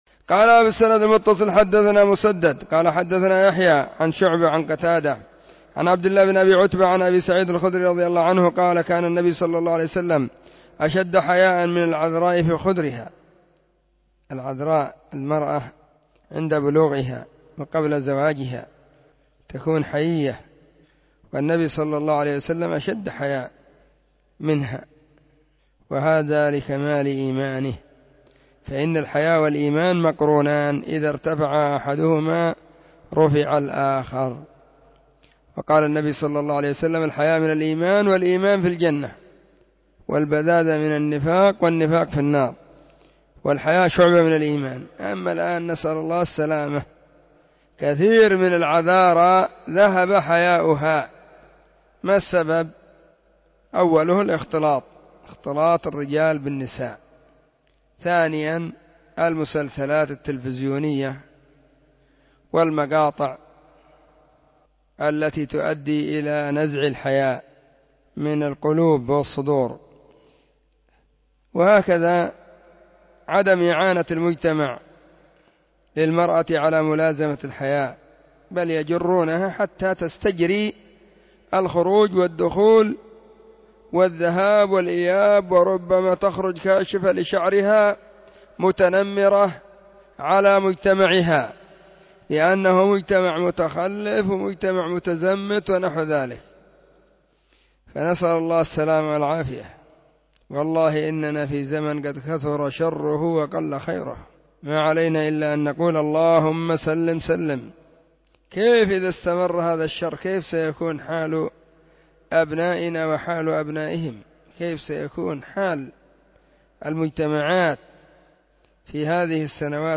📢 مسجد الصحابة بالغيضة, المهرة، اليمن حرسها الله.